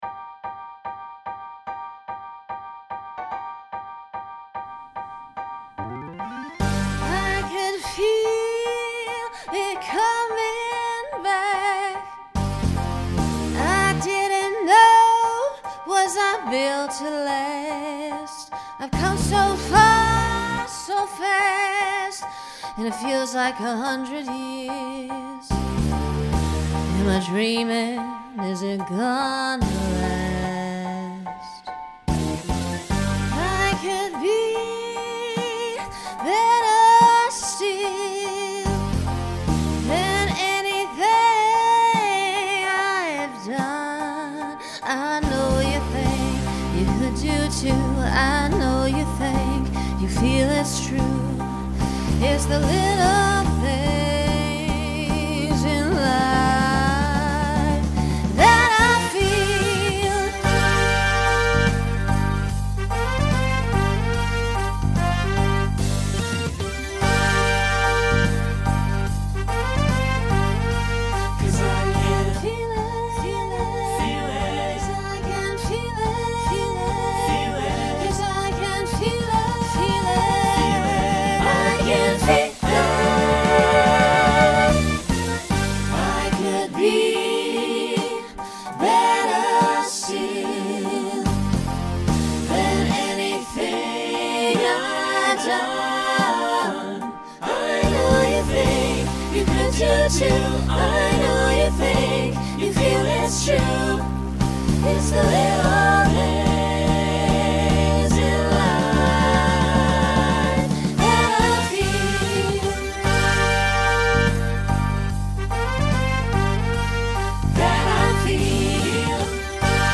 Genre Rock , Swing/Jazz
Voicing SATB